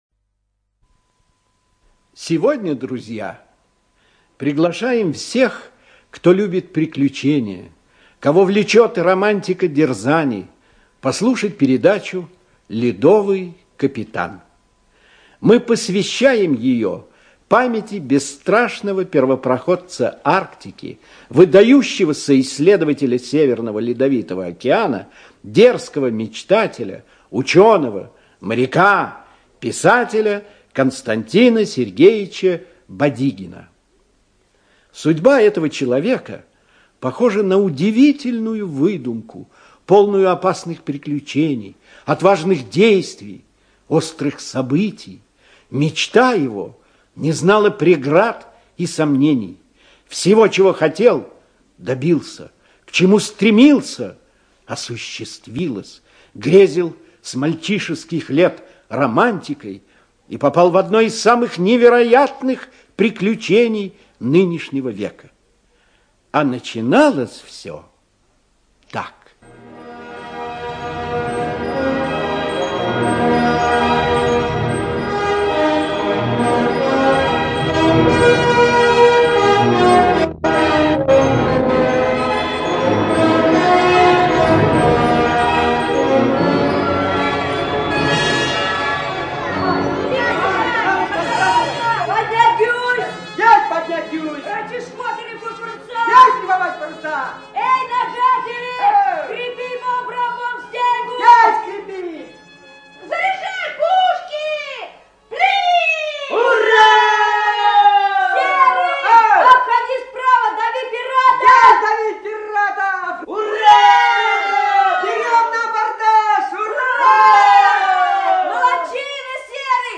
ЖанрДетский радиоспектакль